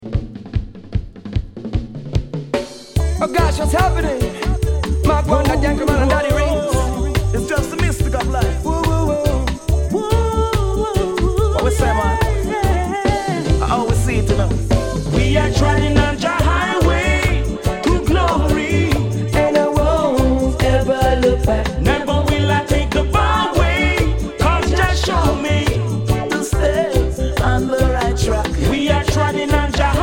Reggae Ska Dancehall Roots Vinyl Schallplatten Records ...